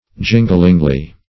jinglingly - definition of jinglingly - synonyms, pronunciation, spelling from Free Dictionary Search Result for " jinglingly" : The Collaborative International Dictionary of English v.0.48: Jinglingly \Jin"gling*ly\, adv.
jinglingly.mp3